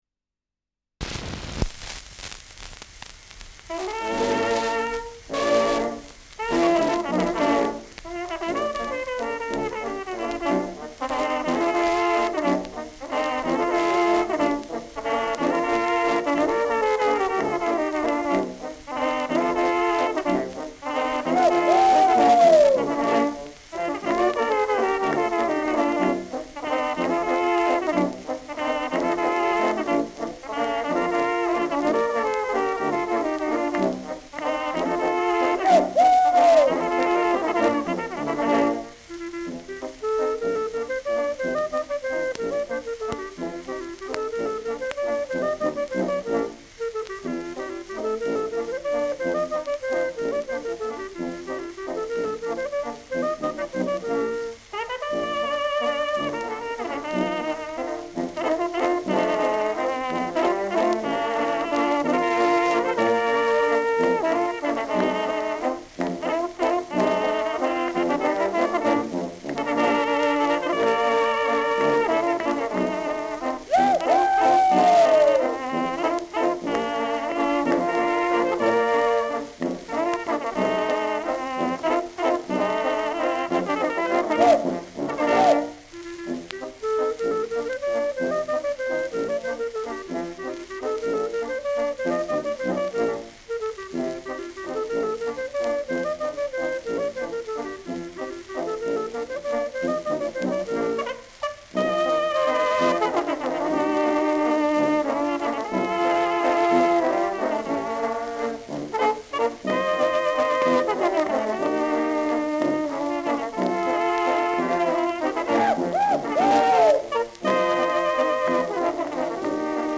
Schellackplatte
leichtes Rauschen
Innviertler Bauern-Trio (Interpretation)